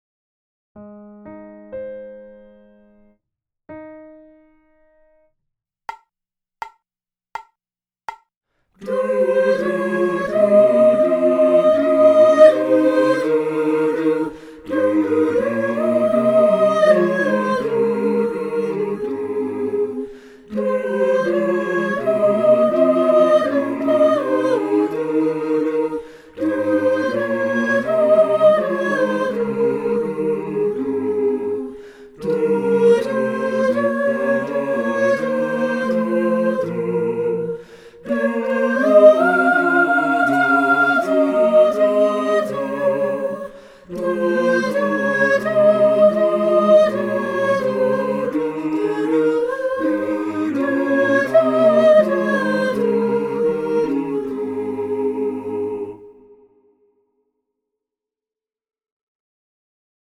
Alto
Alto.mp3